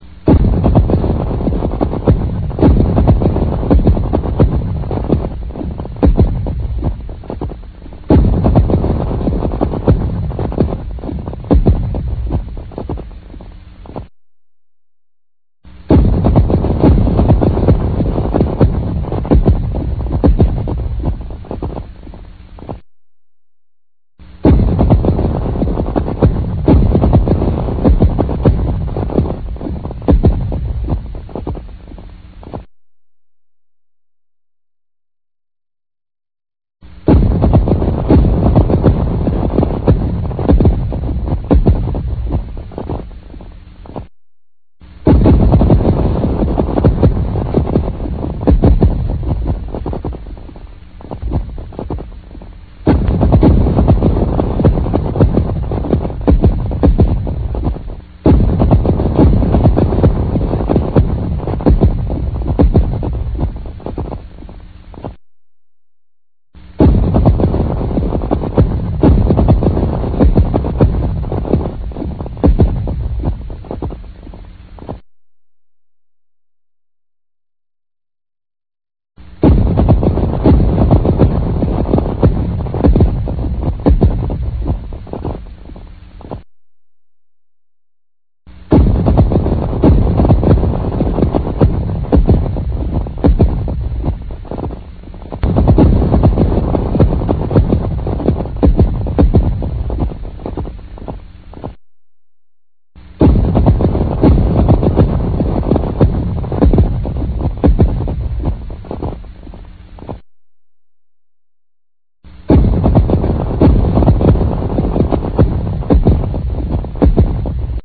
Hear the sound of
cracking and thundering ice in Real Audio
loudericecrack.ram